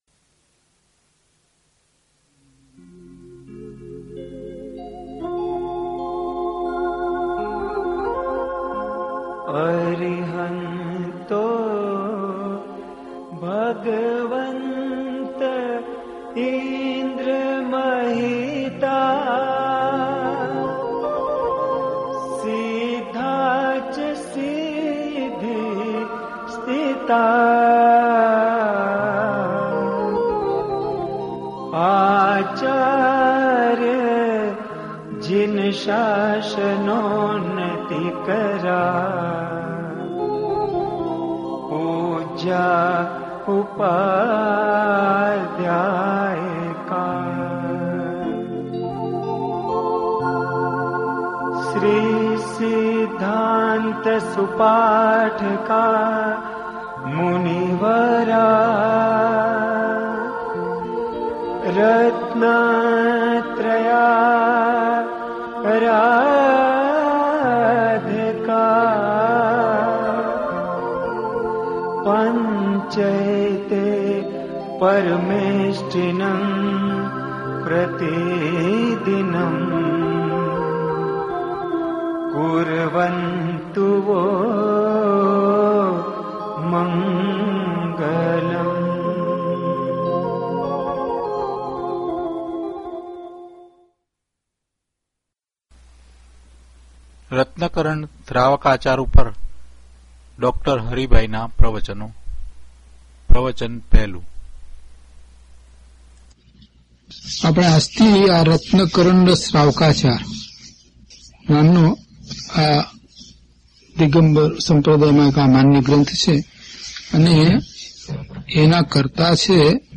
DHP051 Ratnkarandak Shravakachar Shlok 1 to 3 Pravachan.mp3